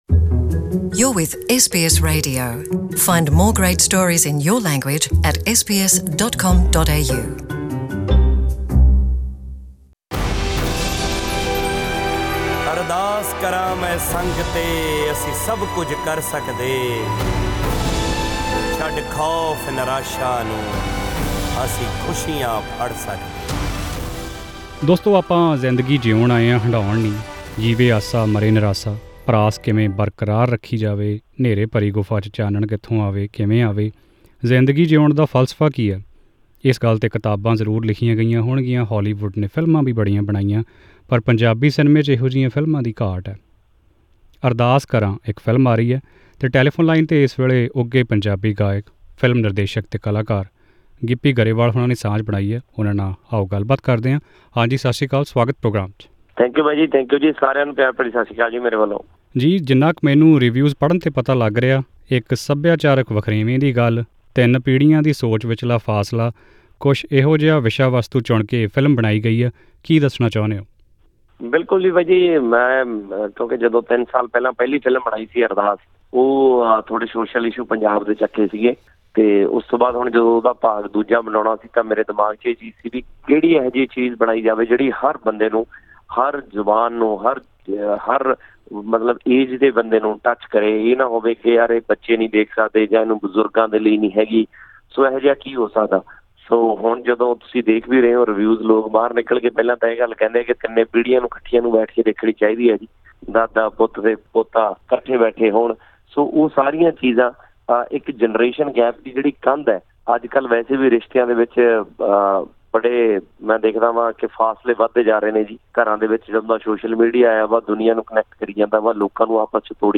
In an exclusive interview with SBS Punjabi , Mr Grewal shared that the movie explores the issue of generation gap faced by many families, particularly in Punjabi families who live outside India.